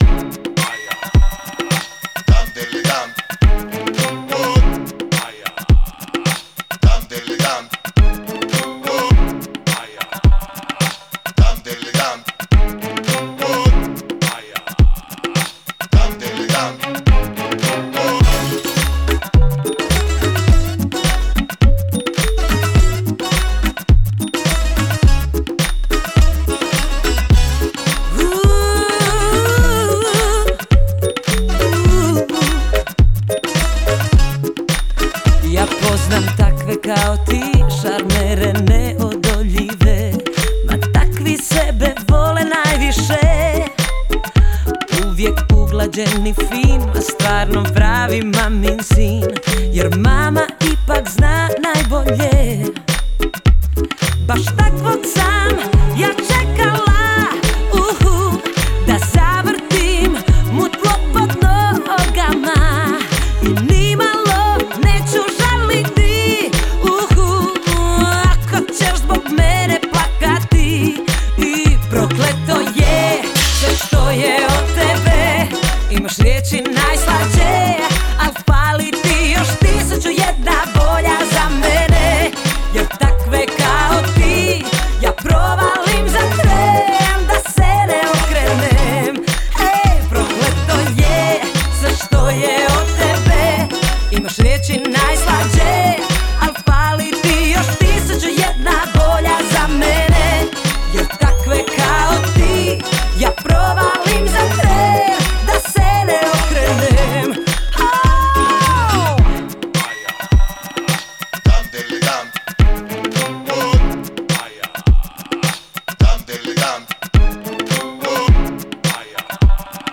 Популярная хорватская поп-певица.